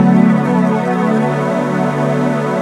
Index of /90_sSampleCDs/Best Service ProSamples vol.10 - House [AKAI] 1CD/Partition C/PADS